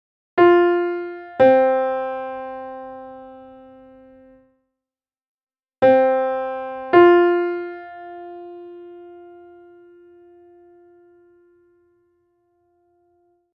The fourth (being an inversion of the fifth after all) behaves in the reverse:
Fourth Descending, Then Resolving Up
Lydian-2-Fourth-Descending-Resolving-Up.mp3